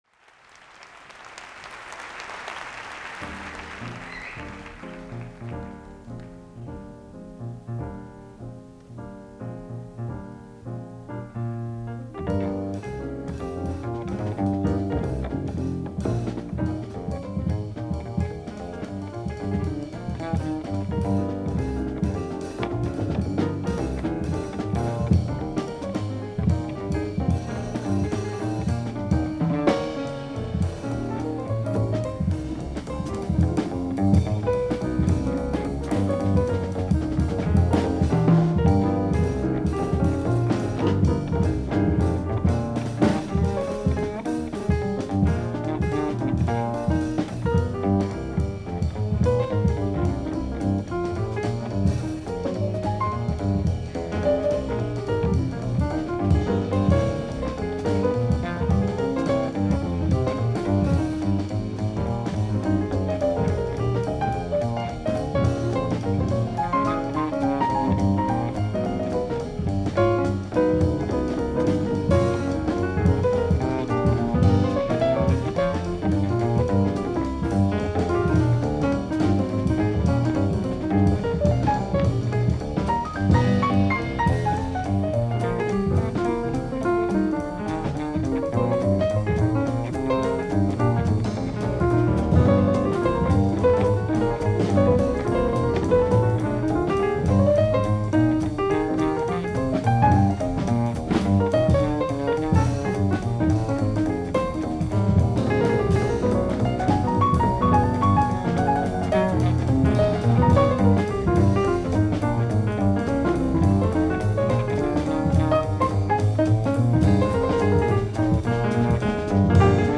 drums
bass
recorded in concert from Osaka, Japan